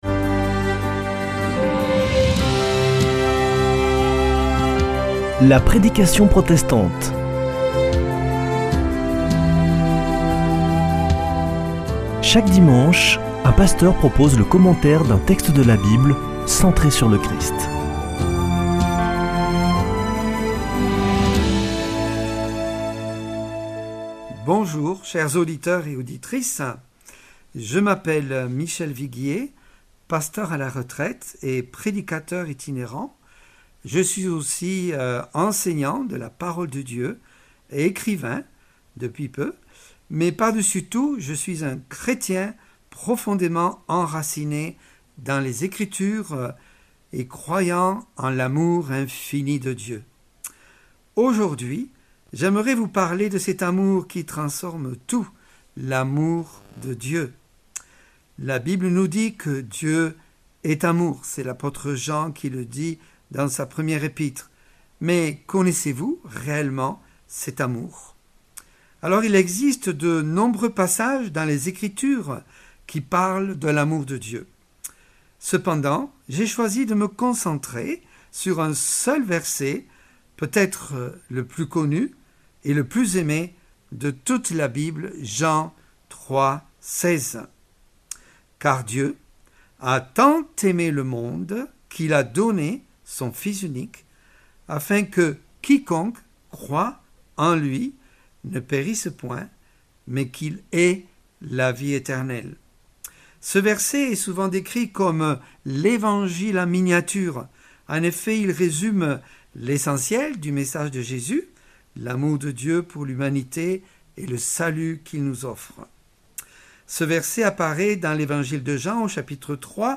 La prédication protestante